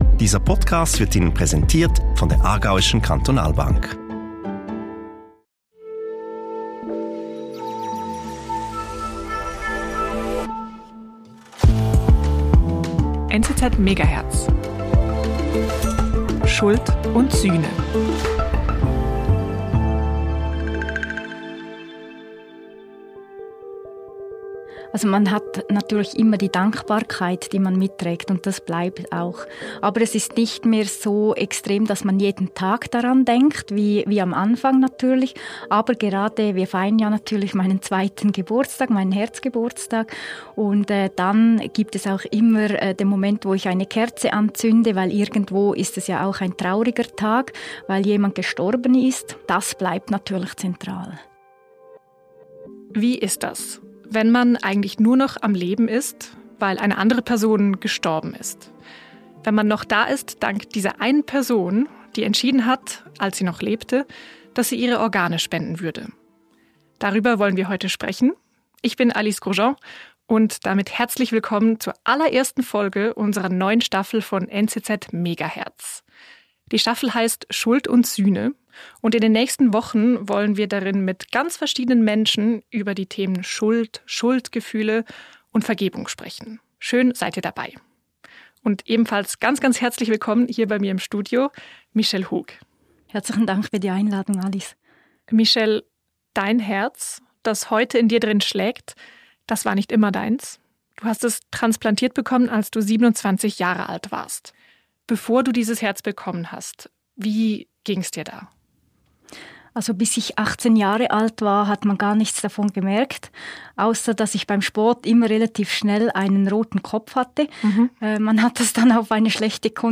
Unsere Hosts fragen sich das auch und sprechen mit Menschen, die Antworten gefunden haben.